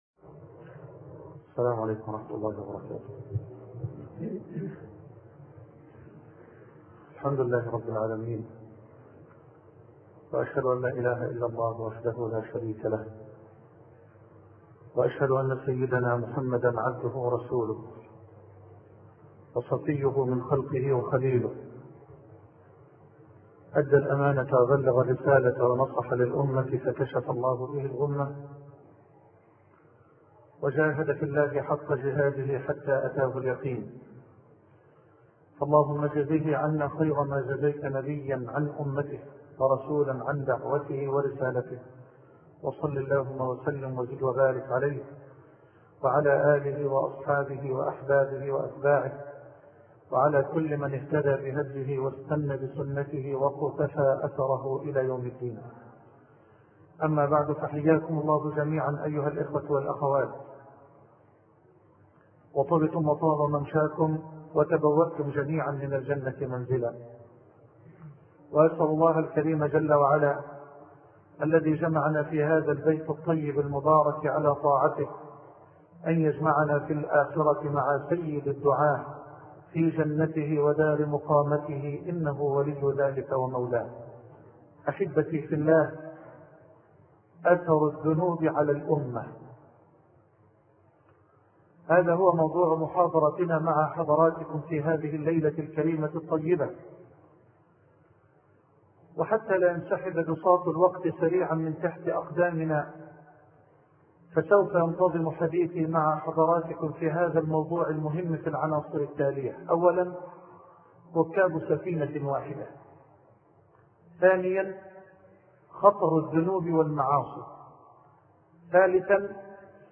شبكة المعرفة الإسلامية | الدروس | أثر الذنوب على الأمة |محمد حسان أثر الذنوب على الأمة محمد حسان  الاشتراك  لدي مشكلة  دخول 7/8/1438 أثر الذنوب على الأمة أثر الذنوب على الأمة Loading the player...